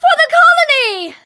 bea_ulti_vo_03.ogg